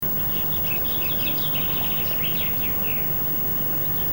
Sangen, der er meget særpræget, er samtidig rimeligt monoton.
/ 97 kb): Sangeren høres i baggrunden, indtil en Bogfinke blander sig og overdøver den.